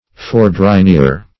Search Result for " fourdrinier" : The Collaborative International Dictionary of English v.0.48: Fourdrinier \Four`dri`nier"\, n. A machine used in making paper; -- so named from an early inventor of improvements in this class of machinery.